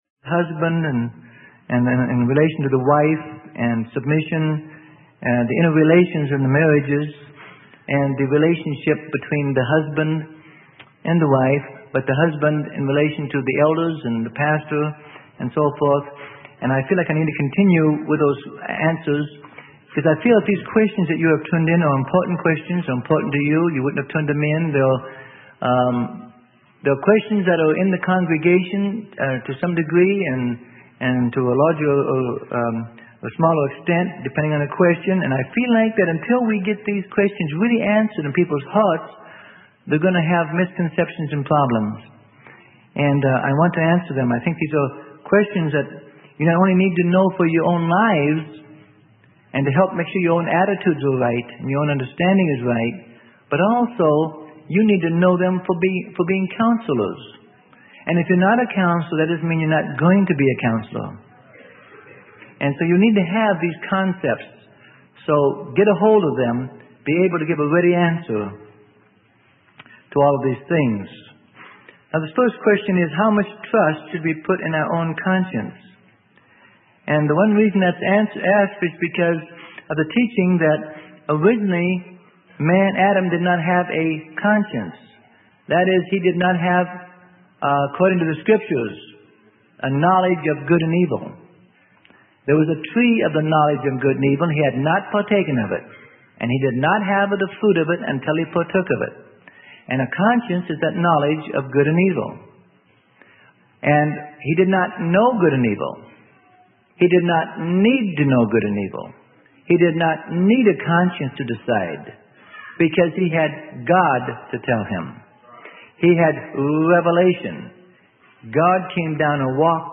Sermon: The Balance on Authority - Part 6 - Freely Given Online Library